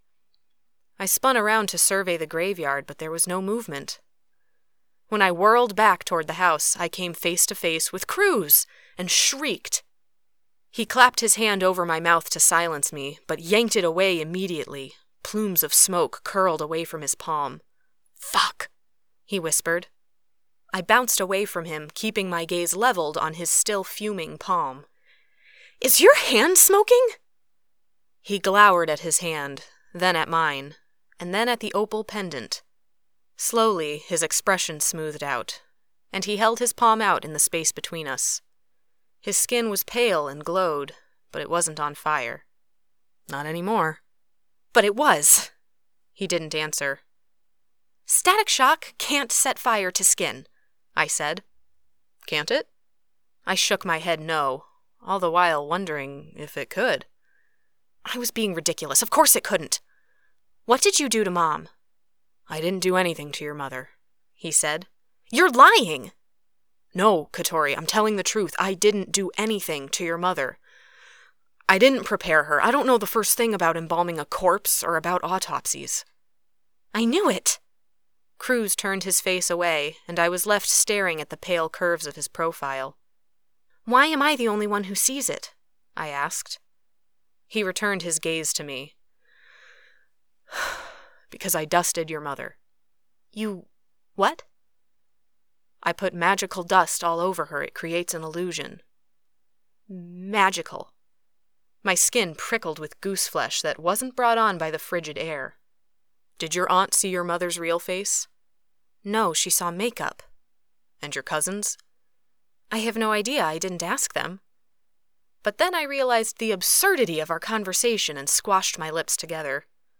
audiobook narrator specializing in young adult fiction